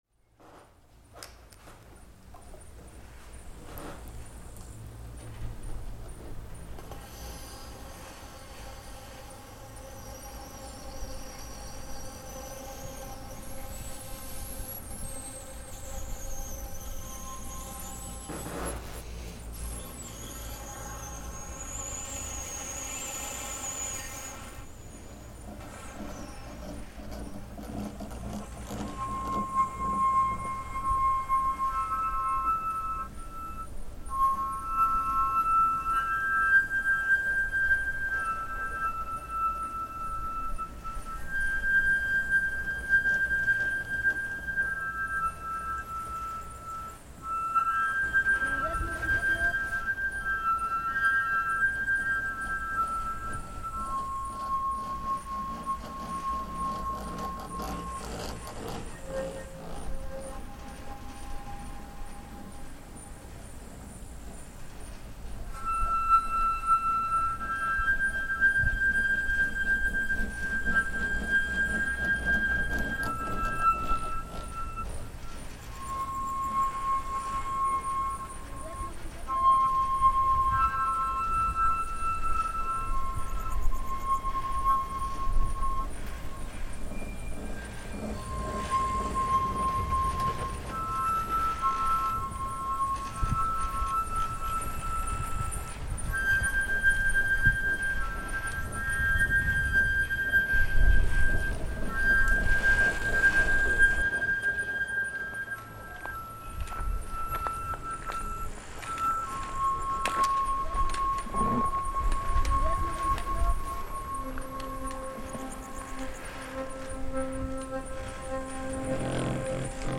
Ski lift in Pescul reimagined